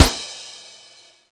jump3.wav